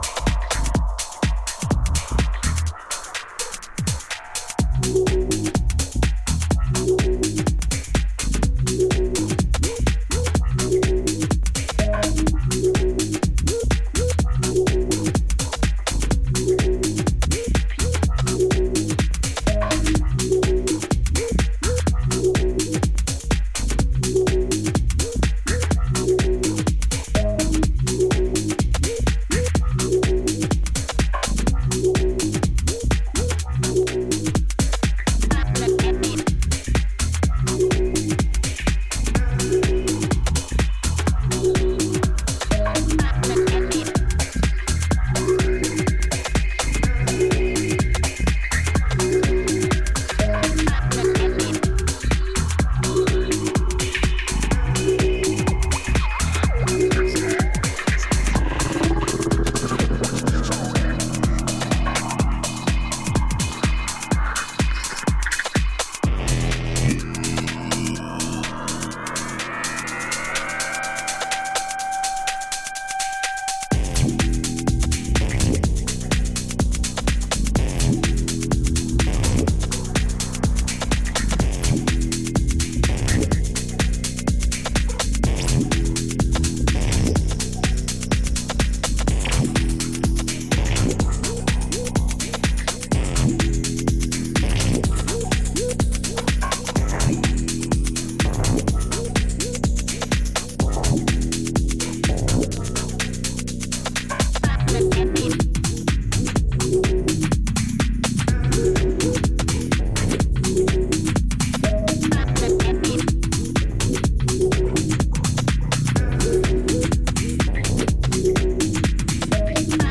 120台の落ち着いたテンポで展開するソリッドなグルーヴはダンサーの気分を高揚させるフロアムードの下地作りにバッチリ。